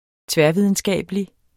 Udtale [ ˈtvεɐ̯- ]